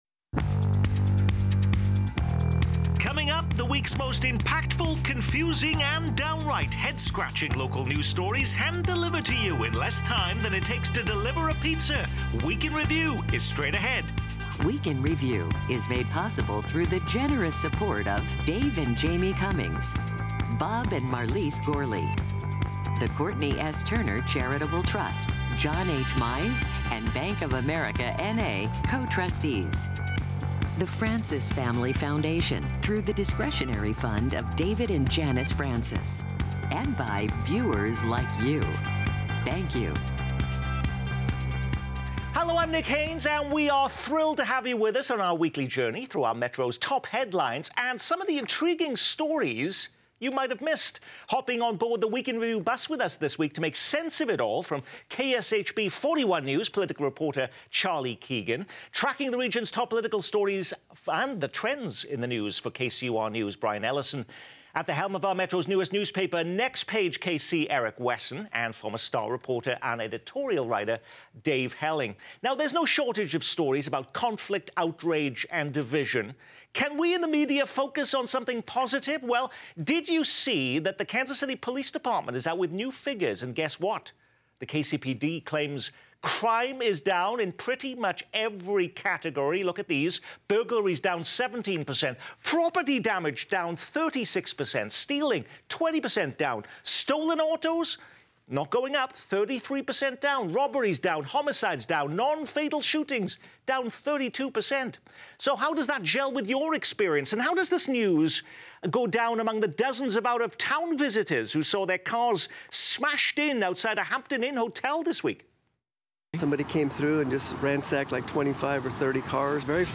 Kansas City Week In Review offers an in-depth view on the top stories of the week with newsmakers and guest experts sharing their insight and perspective.